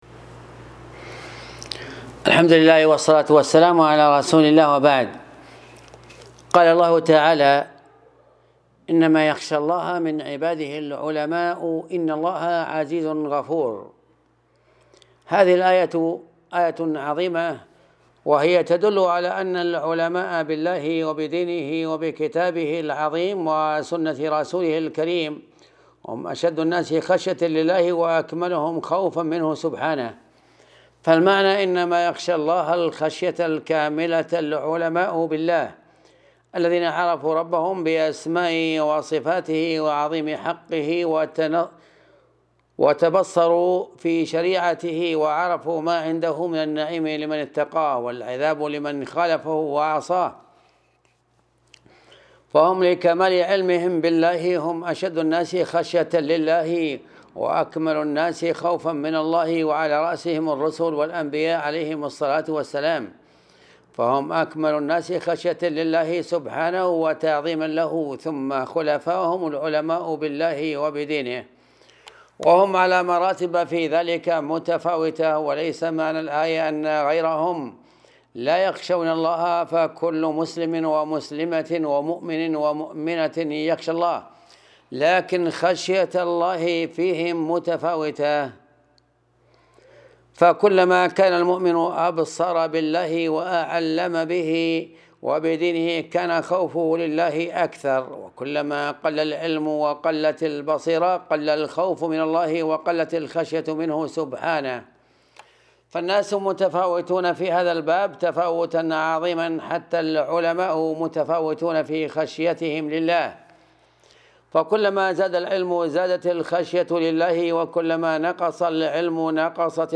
الدرس 39 قوله تعالى إنما يخشى الله من عباده العلماء